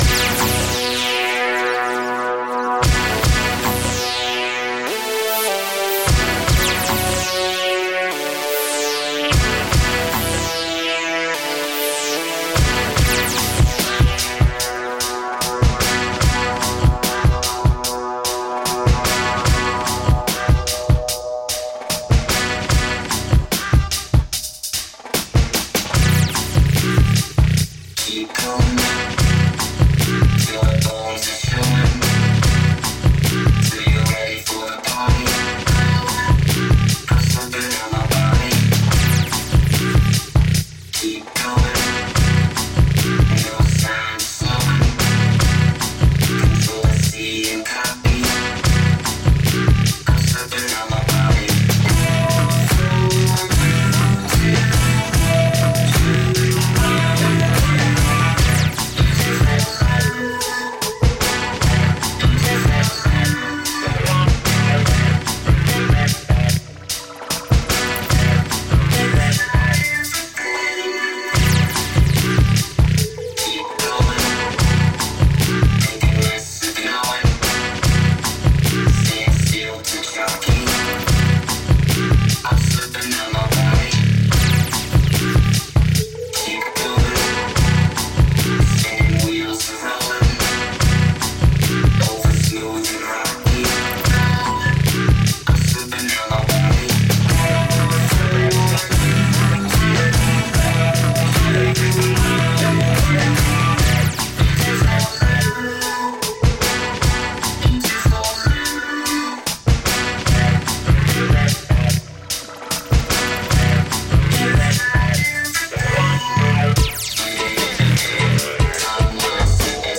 Tagged as: Electro Rock, Funk, Electronica, Hard Electronic